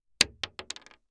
Bullet Shell 9mm Drop On Solid Wood 3 Sound
weapon